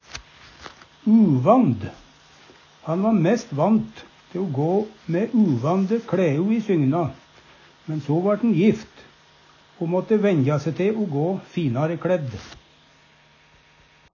uvand - Numedalsmål (en-US)
Høyr på uttala Ordklasse: Adjektiv Attende til søk